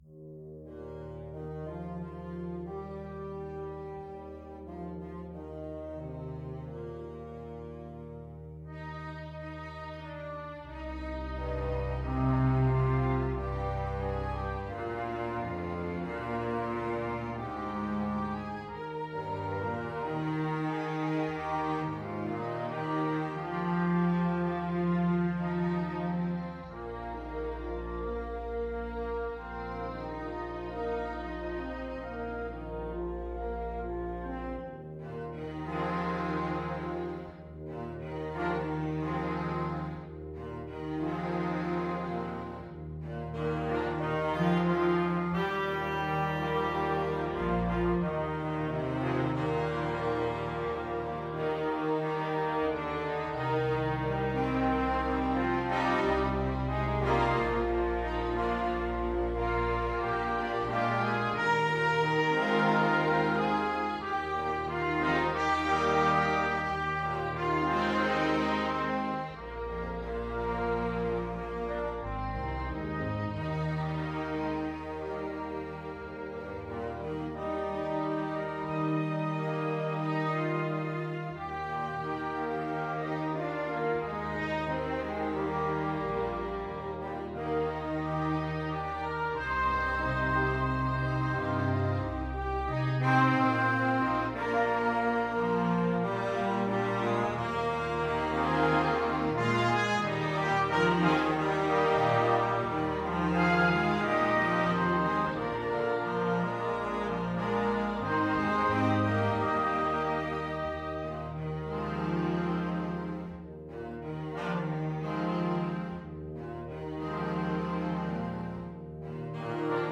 FluteTrumpetAlto Saxophone
OboeTrumpetBaritone Horn
ClarinetFrench HornTrombone
TromboneTenor Saxophone
Baritone SaxophoneTrombone
Tuba
Andante cantabile = c. 90
4/4 (View more 4/4 Music)